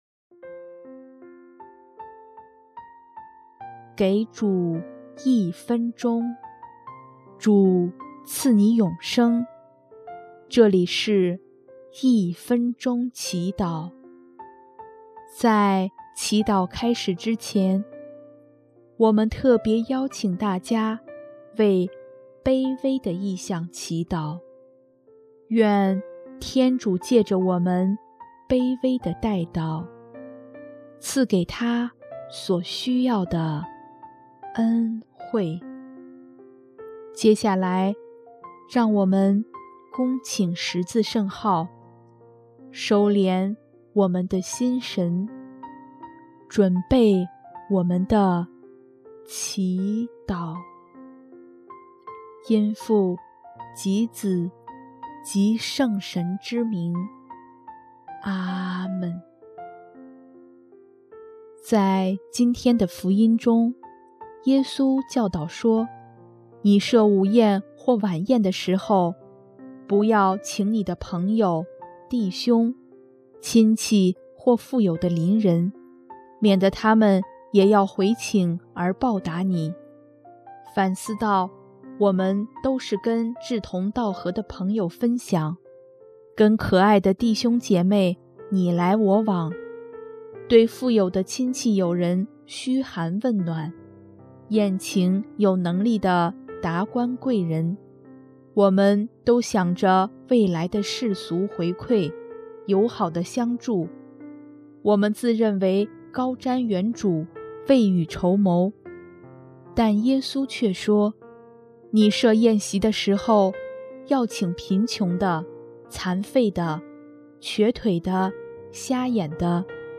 【一分钟祈祷】|11月4日 将真正的爱实践于人间